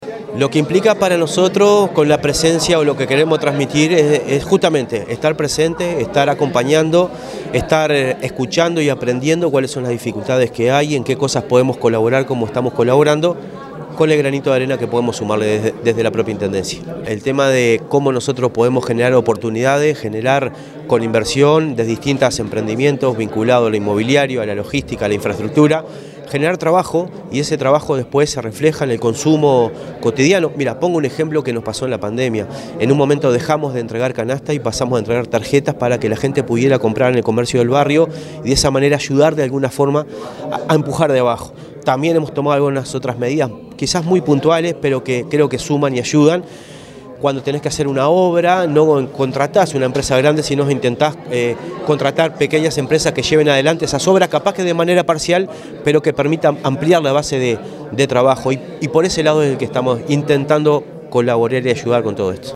El Intendente de Canelones en funciones, Marcelo Metediera, estuvo presente en esta instancia y aprovechó para referirse a las políticas económicas que desarrolla el Gobierno Departamental para atraer inversores, generar puestos laborales y facilitar la instalación de empresas en el territorio.